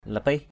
/la-pih/